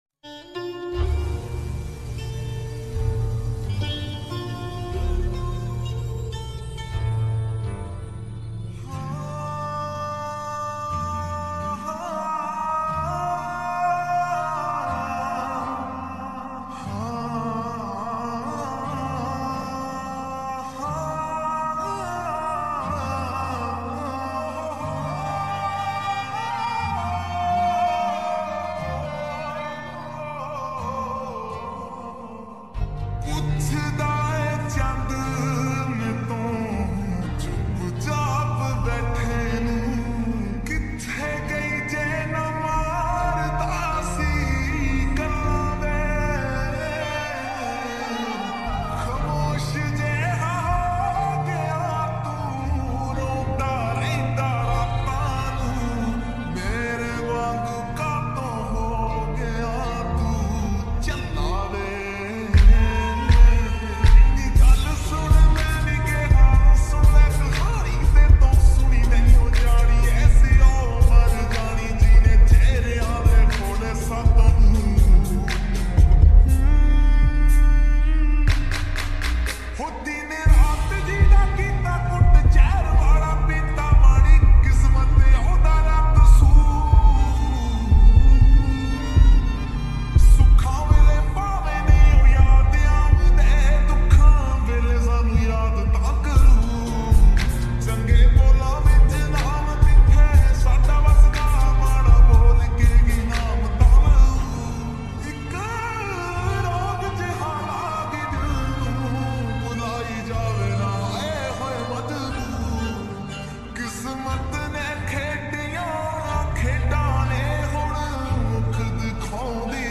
Full song slowed And Reverb
old Sad song